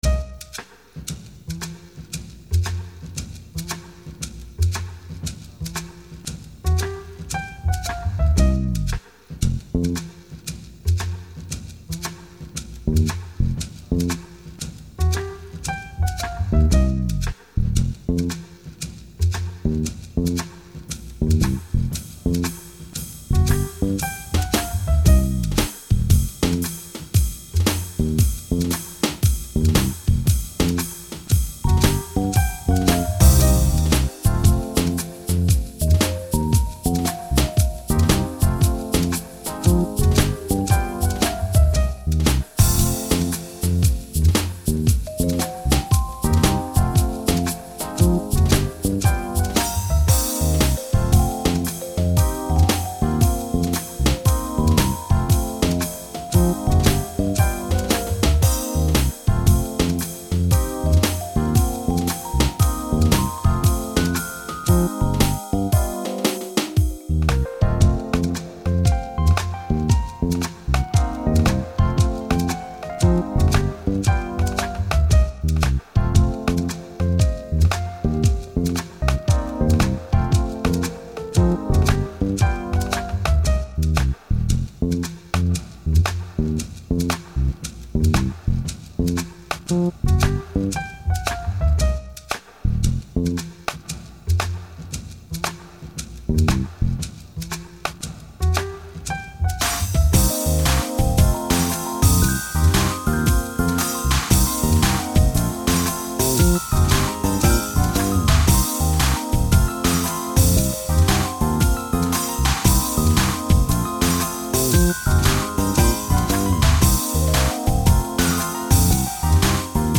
jazz ass ;)